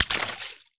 water_splash_small_item_03.wav